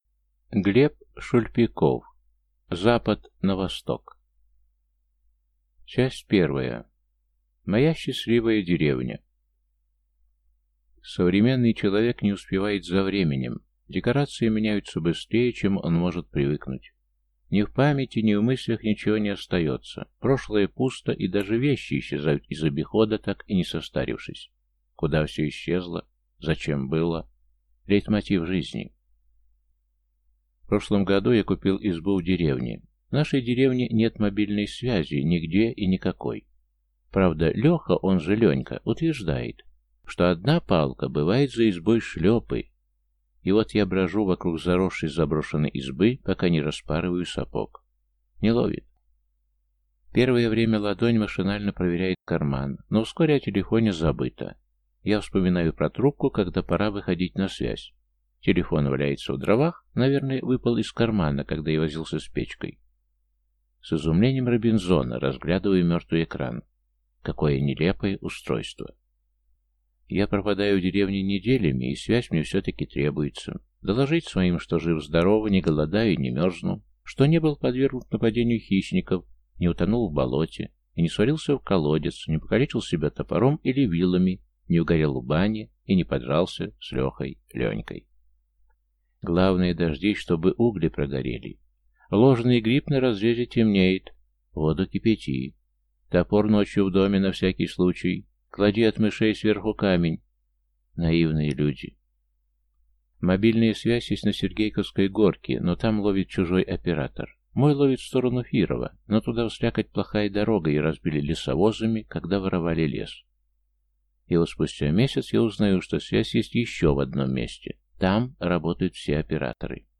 Аудиокнига Запад на Восток | Библиотека аудиокниг